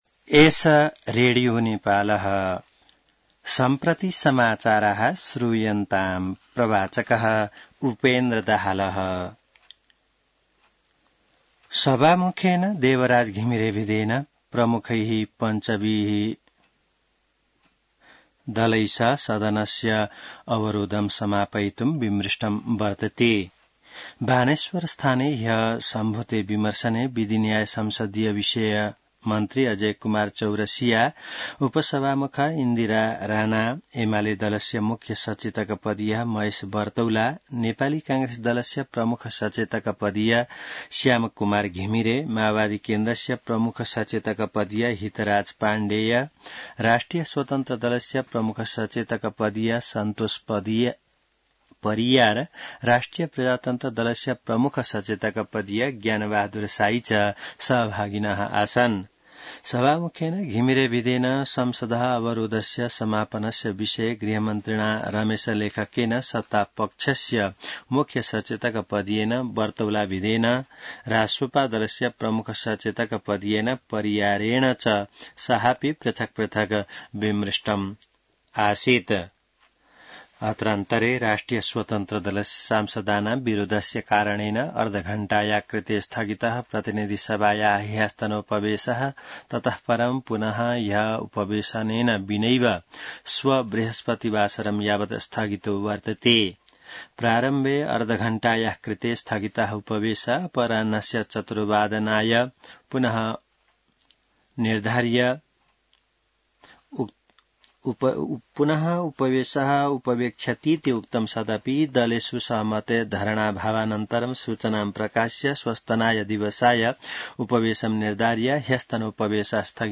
An online outlet of Nepal's national radio broadcaster
संस्कृत समाचार : ४ असार , २०८२